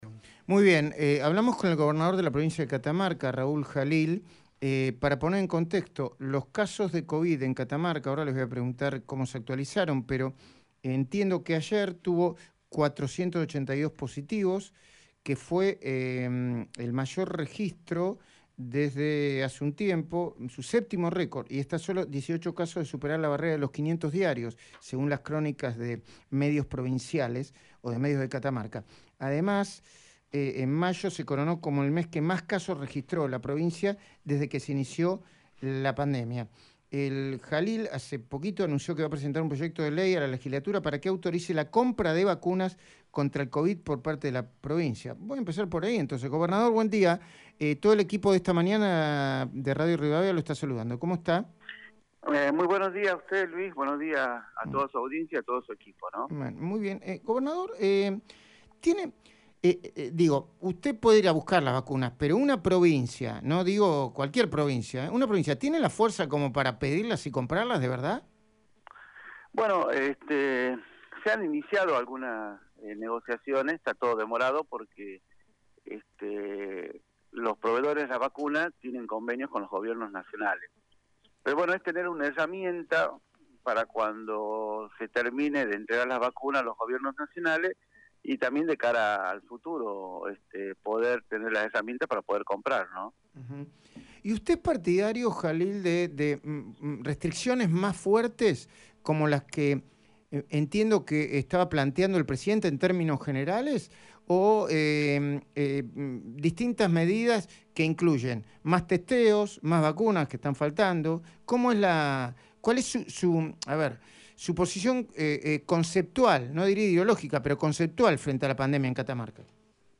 EL gobernador Raúl Jalil habló con Luis Majul en Radio Rivadavia sobre las medidas de restricción que considera necesarias para frenar el aumento de casos en la provincia.